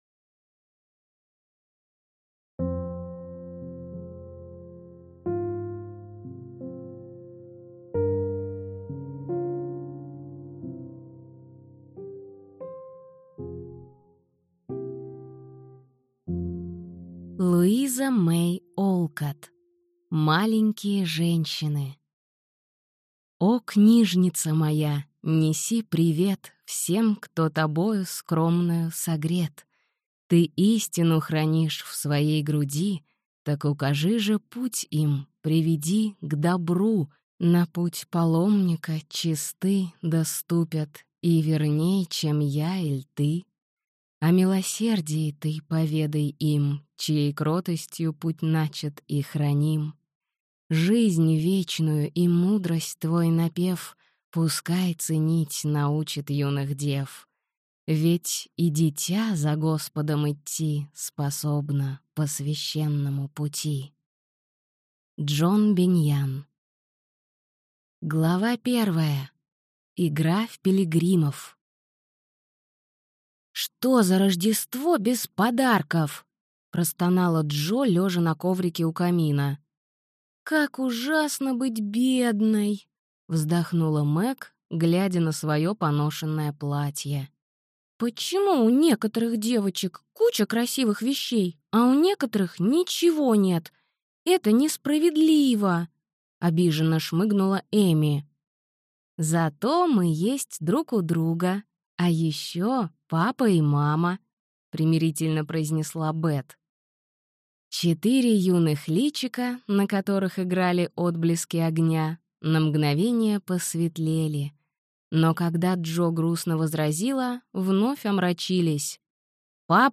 Аудиокнига Маленькие женщины | Библиотека аудиокниг